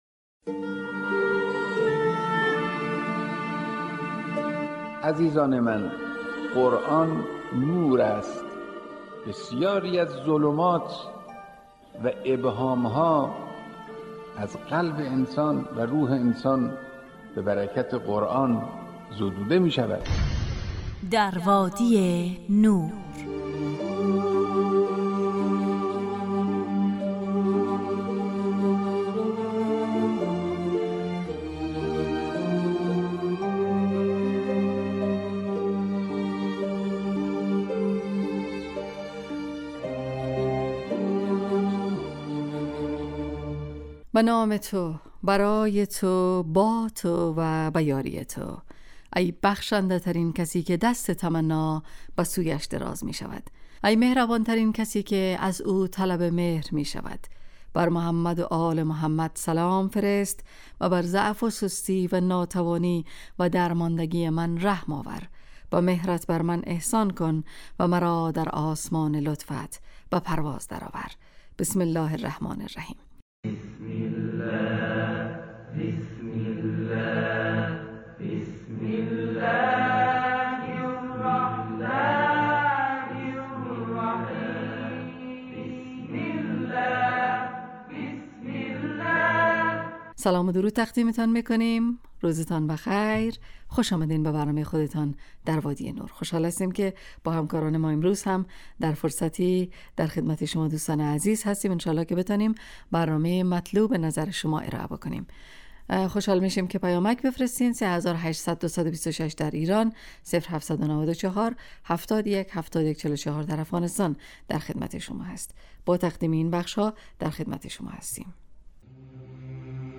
در وادی نور برنامه ای 45 دقیقه ای با موضوعات قرآنی روزهای فرد: ( قرآن و عترت،طلایه داران تلاوت ، دانستنیهای قرآنی، ایستگاه تلاوت، تفسیر روان و آموزه های...